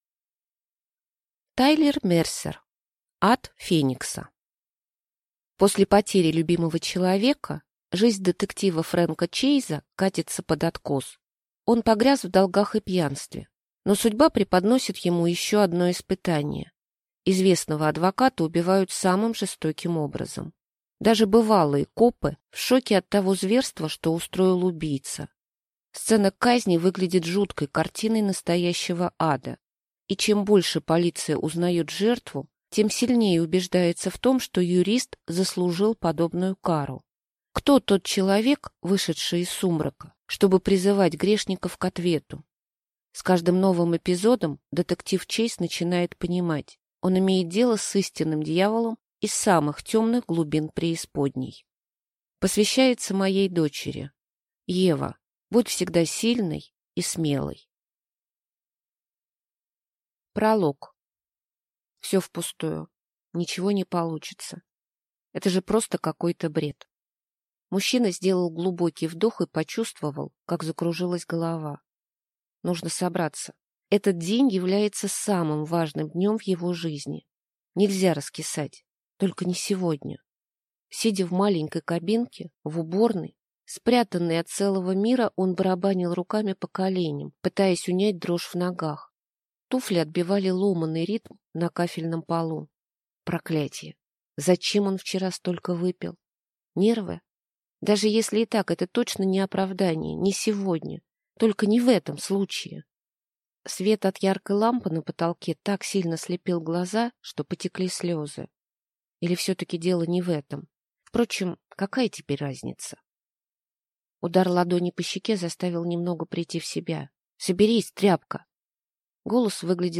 Аудиокнига Ад Феникса | Библиотека аудиокниг